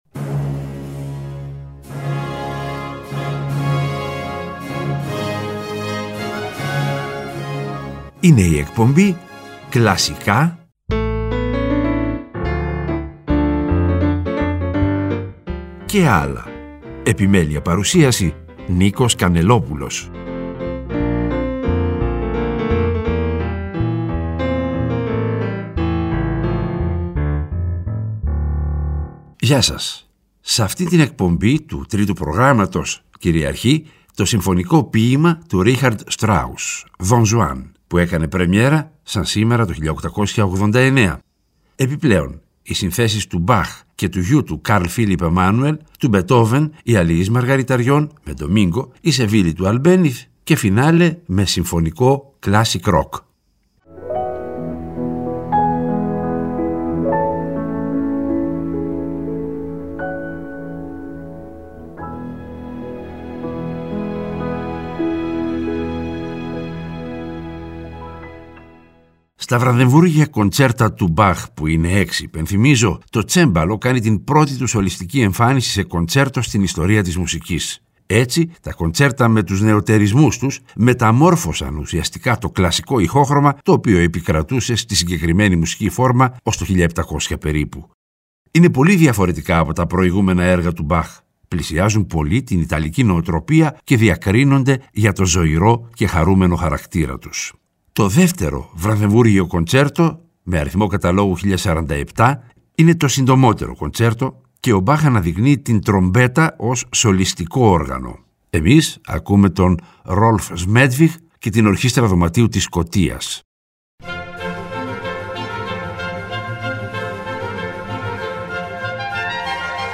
Σ’ αυτήν την εκπομπή κυριαρχεί το συμφωνικό ποίημα του Ρίχαρντ Στράους “Don Juan” (πρεμ. 11/11/1889). Επιπλέον, συνθέσεις του Μπαχ και του γιου του Καρλ Φίλιπ Εμάνουελ, του Μπετόβεν, οι «Αλιείς Μαργαριταριών» με Ντομίνγκο, η «Σεβίλλη» του Αλμπένιθ και φινάλε με Classic Rock.